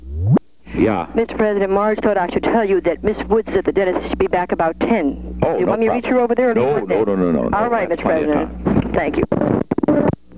Location: White House Telephone
The White House operator talked with the President.